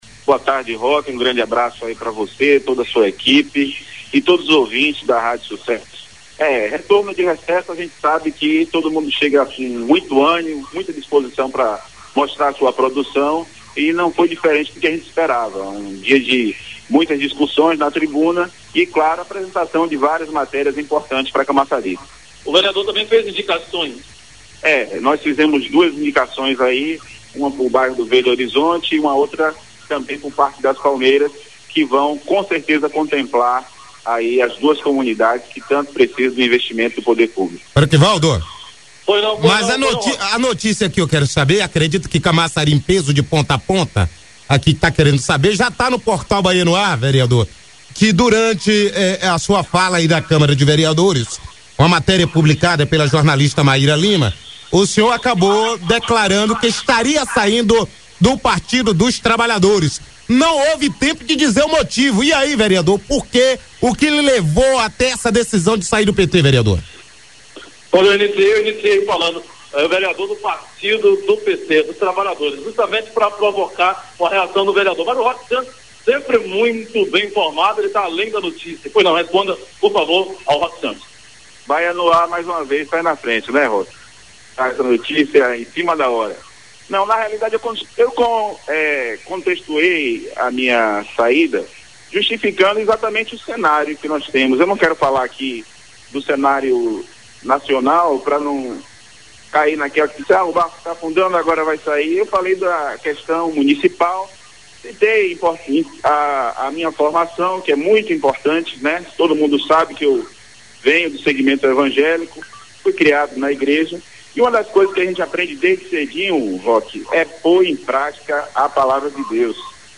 Áudio: vereador Oziel anuncia desligamento do PT – Escute entrevista na íntegra